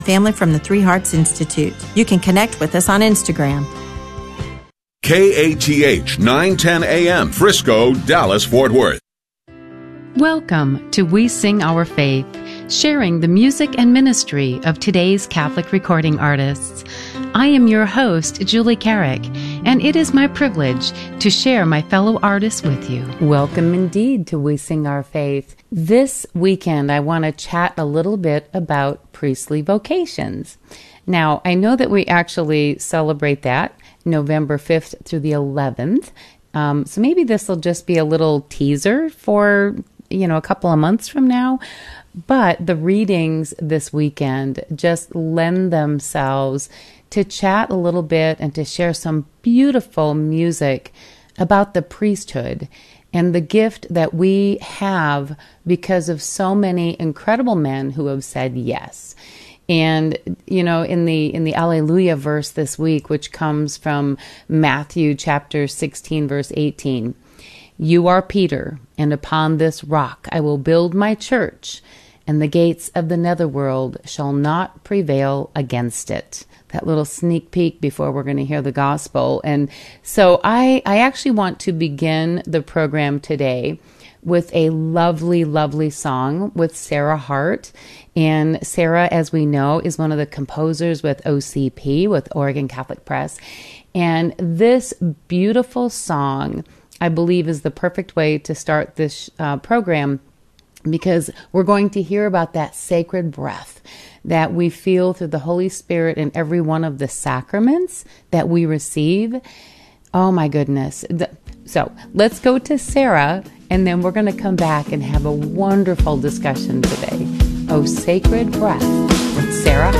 A new uplifting Catholic music show